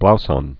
(blousŏn, blzŏn)